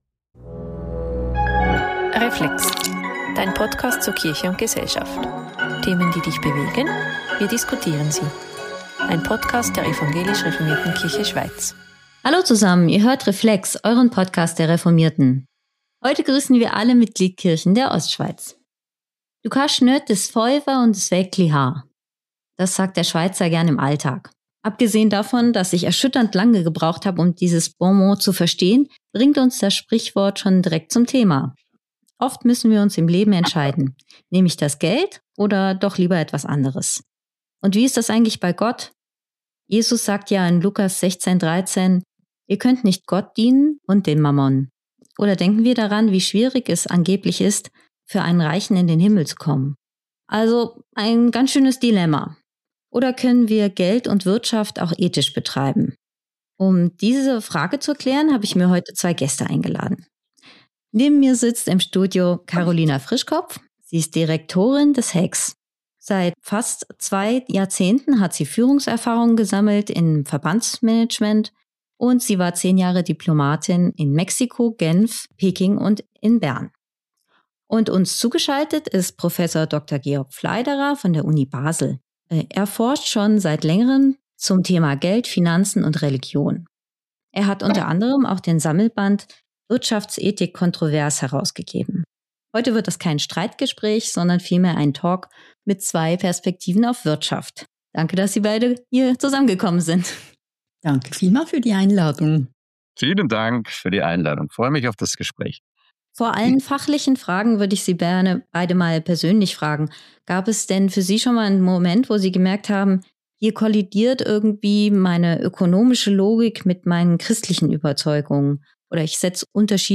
Ist Kapitalismus nun unchristlich oder ist die Frage nach Geld oder Gott gar nicht so sinnvoll? Eine ehrliche Diskussion über Ethik, Verantwortung und die grossen Probleme global und lokal.